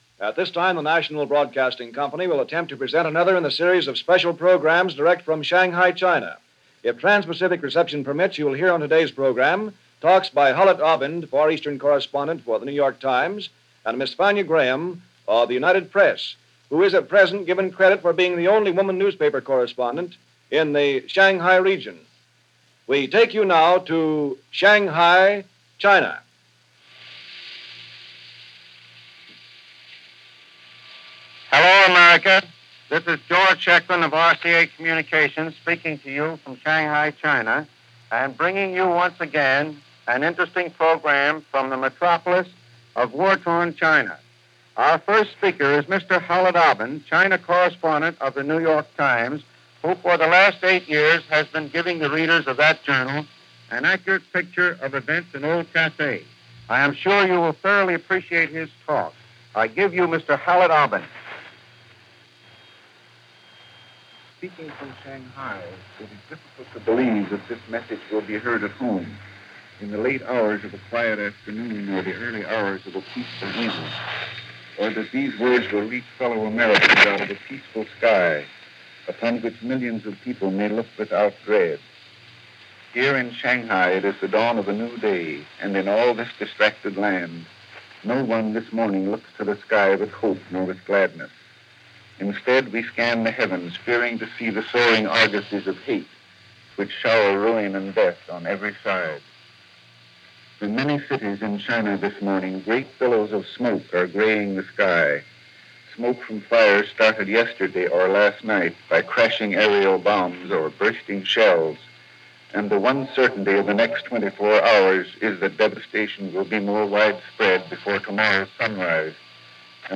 September 22, 1937 - Shanghai Calling - Preview Of Coming Attractions - The Second Sino-Japanese War (Conflict) - reports direct from China.
September 22, 1937 – Special Program from Shanghai, China – News Reports – NBC Red Network – Gordon Skene Sound Collection –